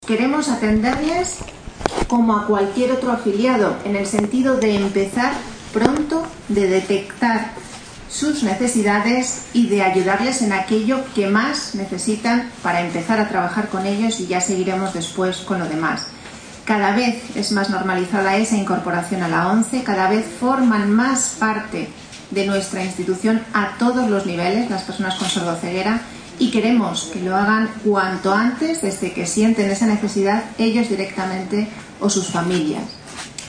El acto central se celebró en el Complejo Deportivo y Cultural de la ONCE en Madrid, con el lema “Caminando sin barreras”.